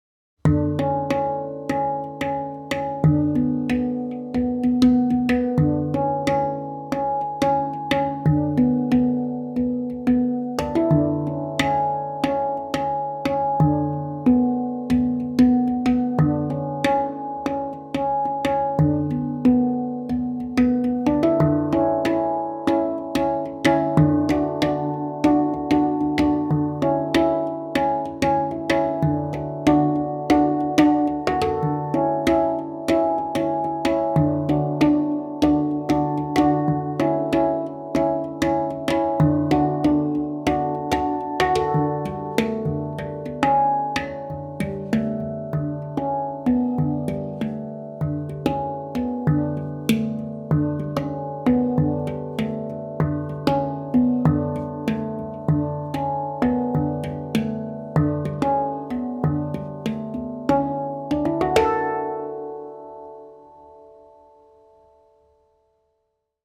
Moon II Handpan D-dur
Den gir en klar, lys klang og lang sustain, perfekt for meditative og stemningsfulle musikkopplevelser.
• Stemt i D-dur for en lys og harmonisk lyd.
• Lang sustain, rask toneutvikling og meditative kvaliteter.
D-dur gir en lys, harmonisk og optimistisk klang, ideell for meditative komposisjoner og improvisasjon.